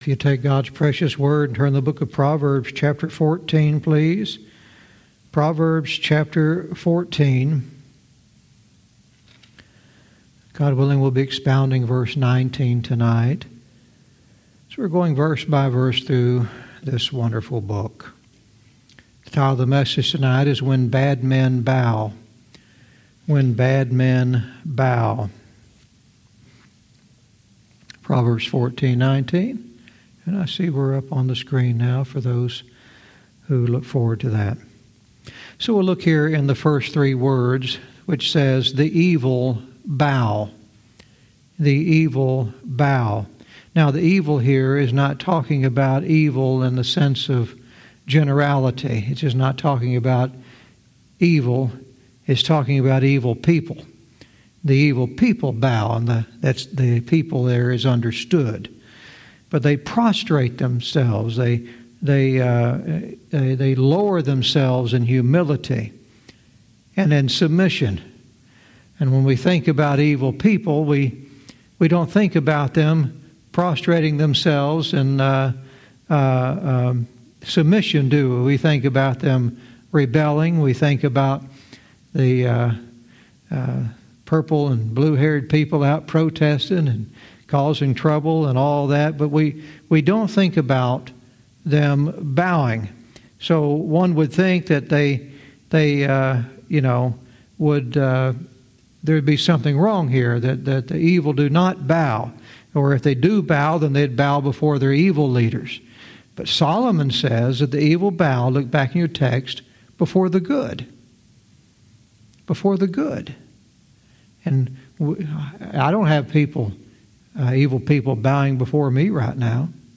Verse by verse teaching - Proverbs 14:19 "When Bad Men Bow"